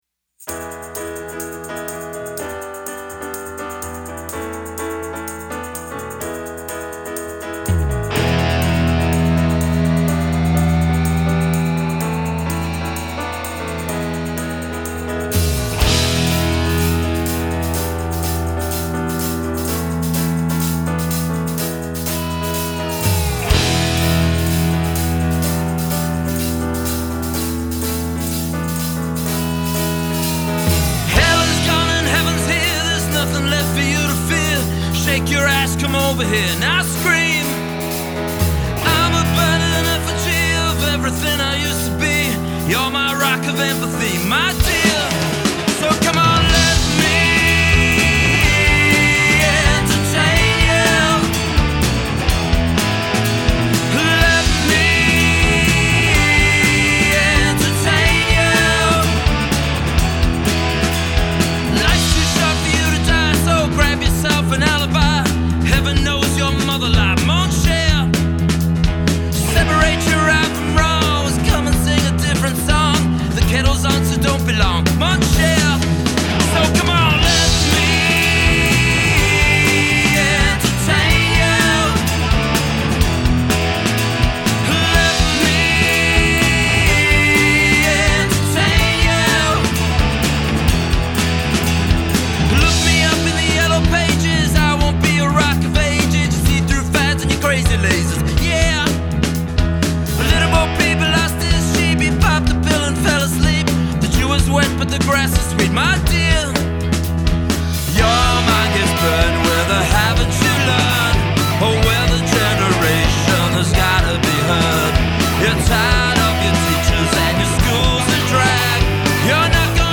Classic Rock and Soul